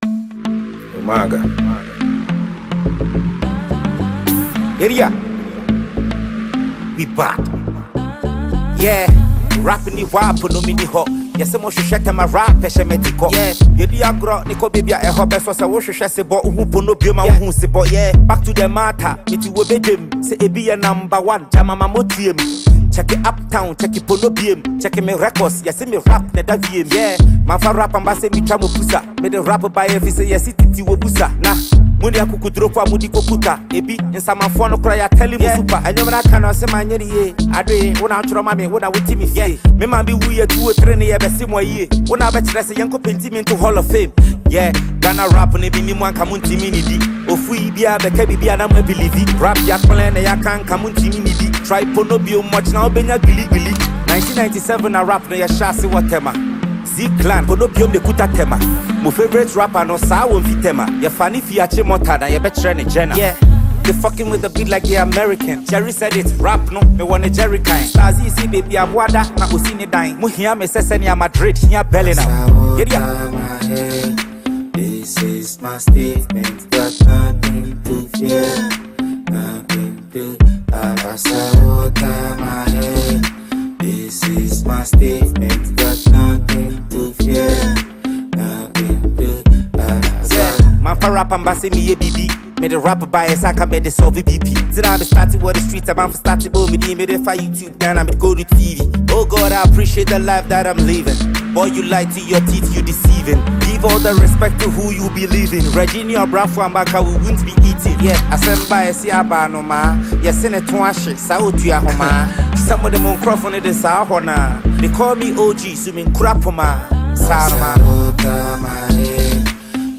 a Ghanaian rapper originating from Tema